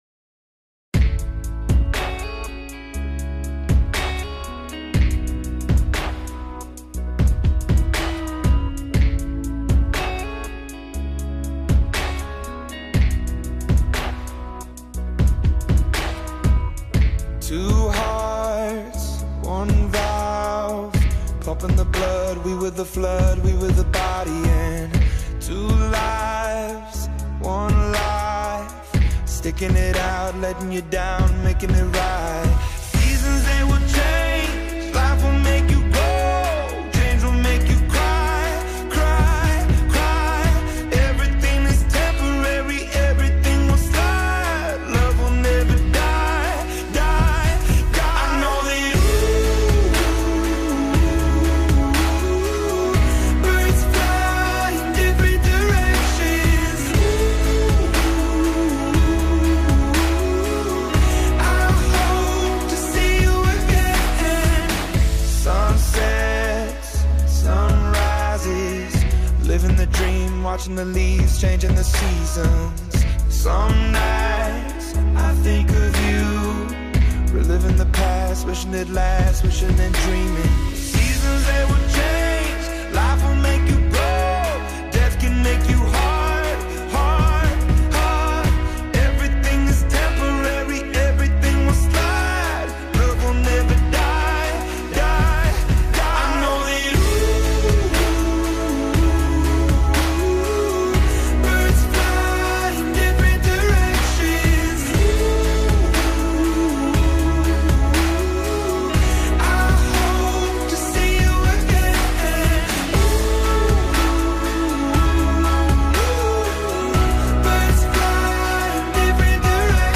birds.mp3